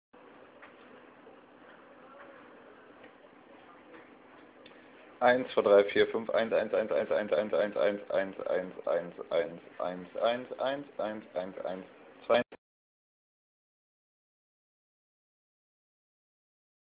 Die MP3s kommen in Mono und mit 18kb/s nicht gerade hochqualitativ aber platzsparend an.